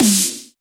描述：陷阱鼓没有808的下降，只有鼓/小鼓/高帽的滚动/。
Tag: 130 bpm Trap Loops Drum Loops 2.49 MB wav Key : Unknown